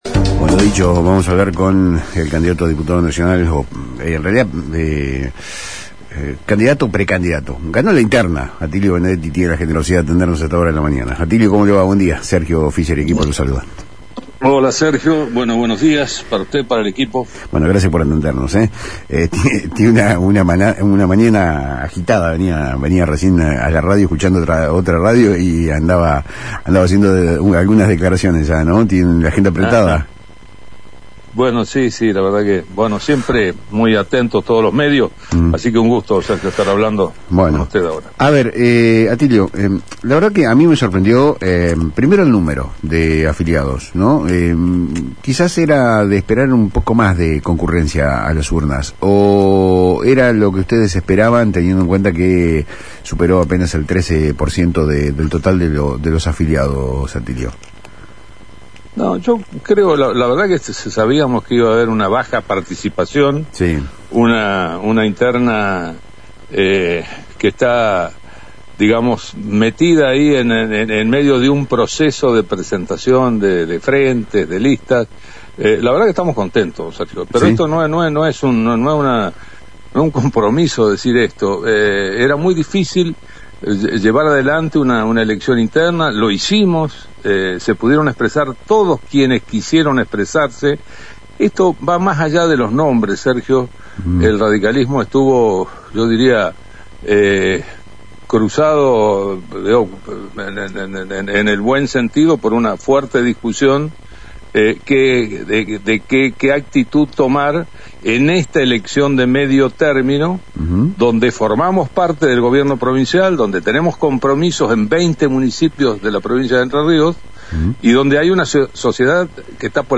En una entrevista radial con el programa Palabras Cruzadas por FM Litoral, el legislador no solo defendió la legitimidad del resultado, sino que también sentó las bases de la estrategia del partido de cara a las próximas elecciones de medio término.